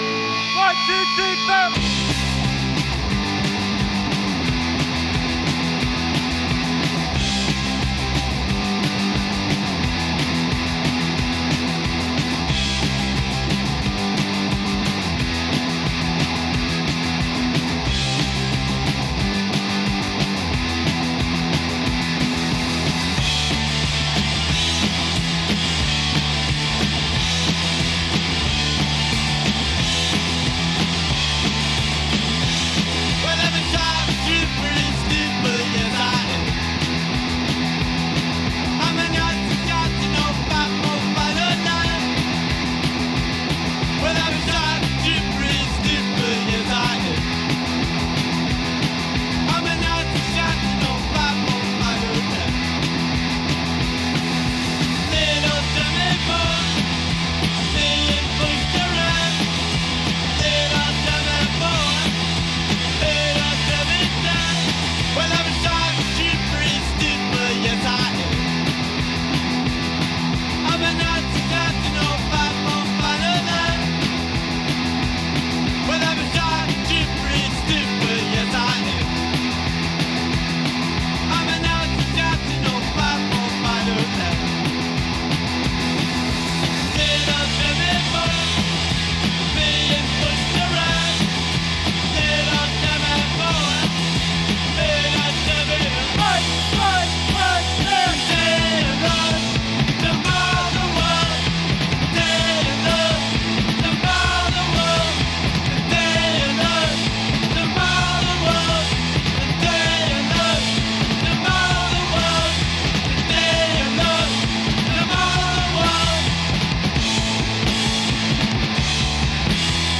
американская рок-группа